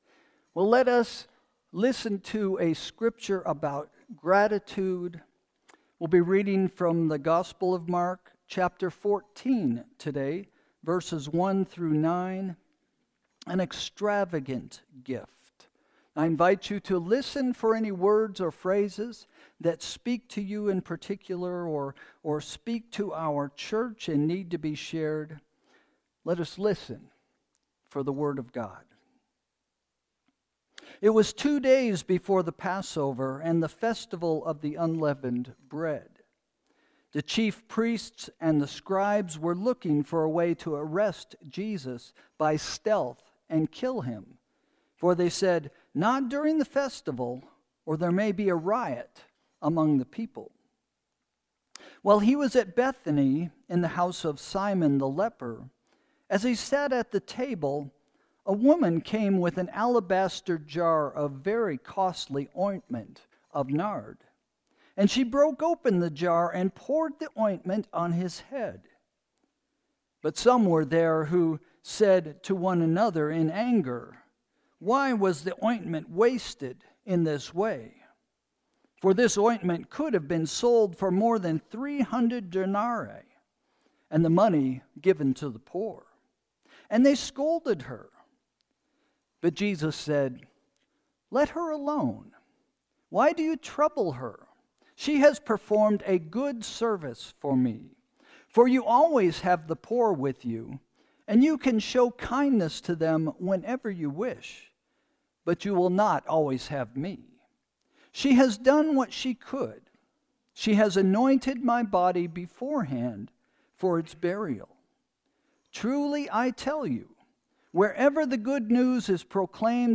Sermon – November 23, 2025 – “Extravagant Gratitude” – First Christian Church